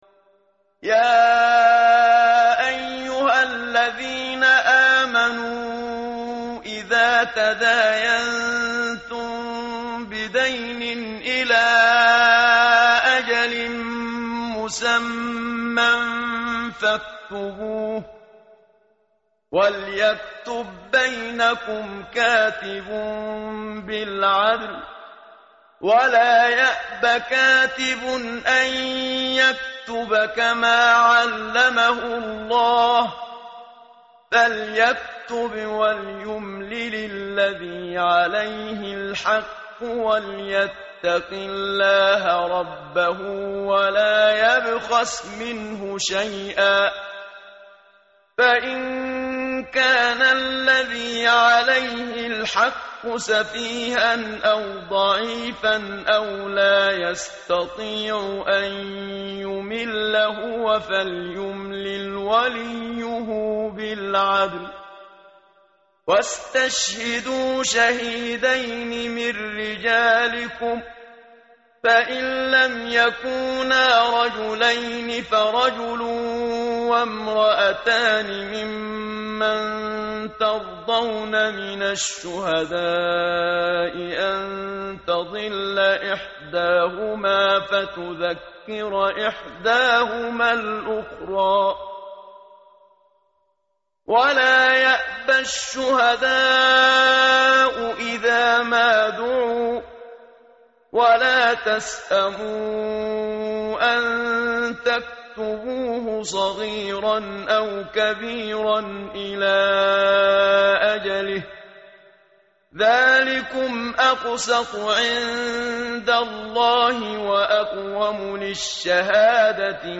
متن قرآن همراه باتلاوت قرآن و ترجمه
tartil_menshavi_page_048.mp3